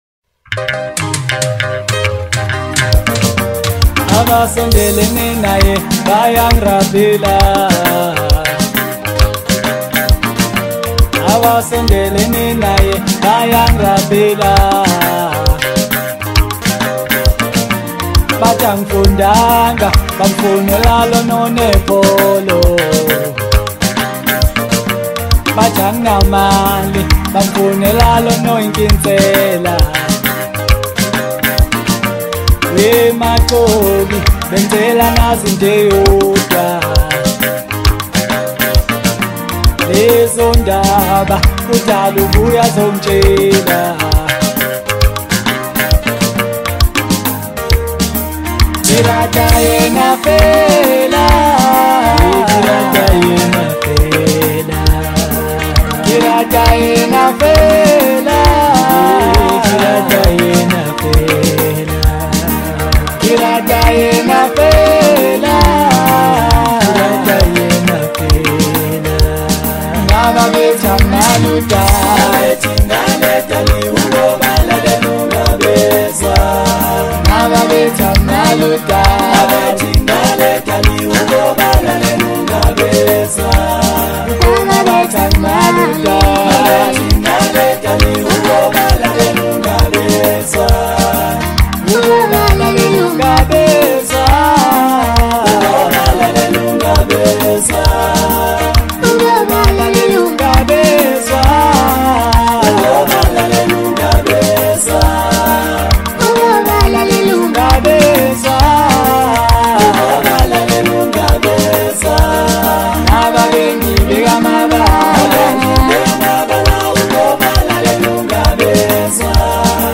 Home » Hip Hop » Kwaito » Maskandi